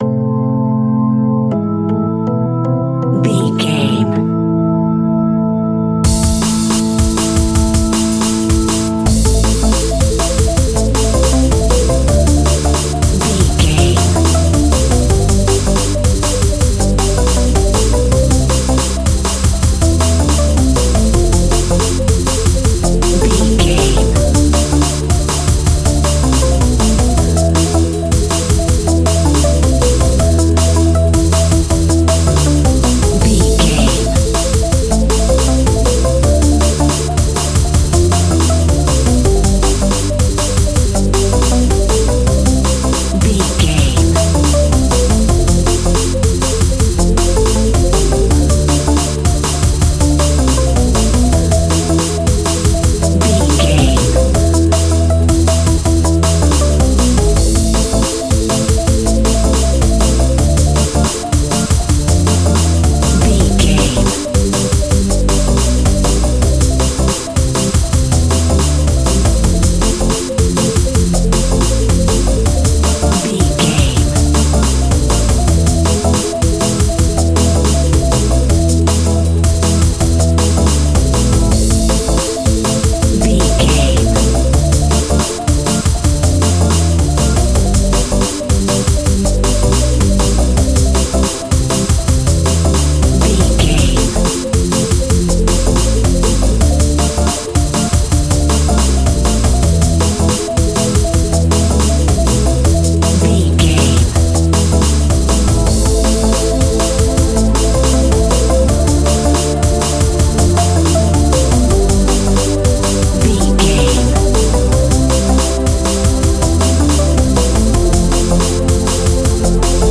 Epic / Action
Fast paced
Aeolian/Minor
aggressive
dark
futuristic
energetic
drum machine
electric organ
electronic
break beat music
synth bass
synth lead
synth pad